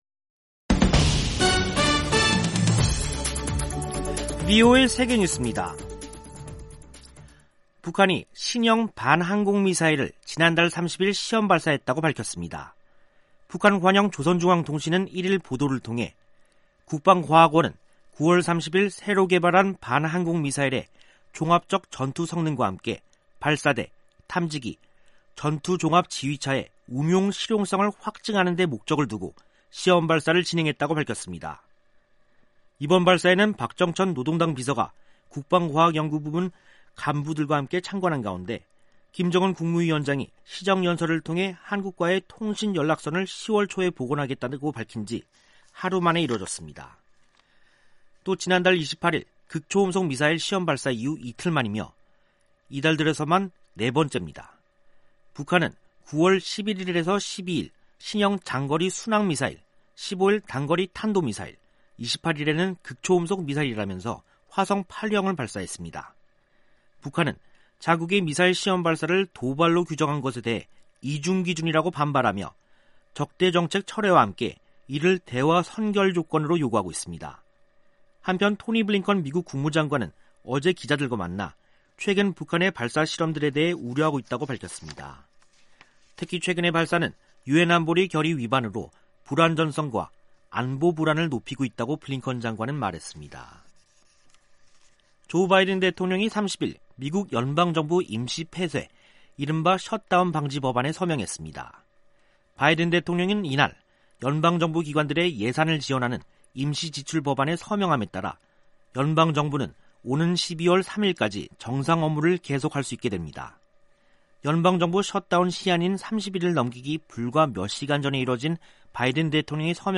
세계 뉴스와 함께 미국의 모든 것을 소개하는 '생방송 여기는 워싱턴입니다', 2021년 10월 1일 저녁 방송입니다. '지구촌 오늘'에서는 미국-러시아 전략 대화에서 실무 그룹 구성에 합의한 소식, '아메리카 나우'에서는 연방정부 셧다운을 막는 임시 지출안에 조 바이든 대통령이 서명한 이야기 전해드립니다.